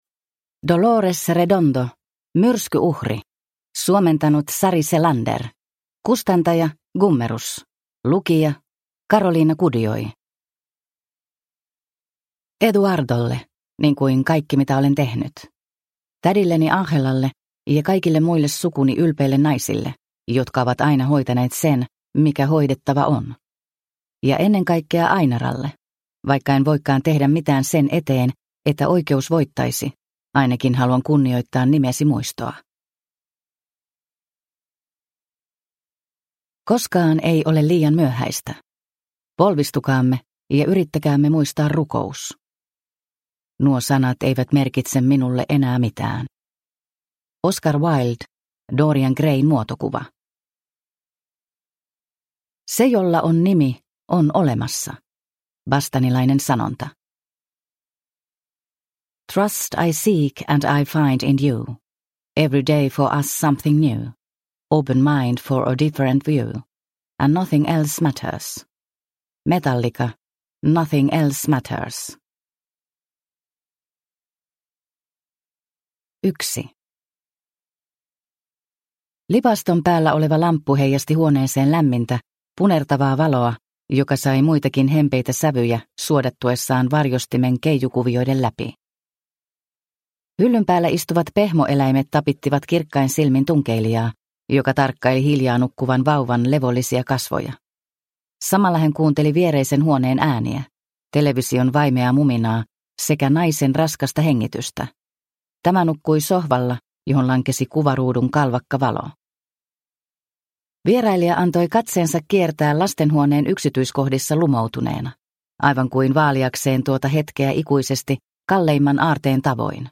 Myrskyuhri – Ljudbok – Laddas ner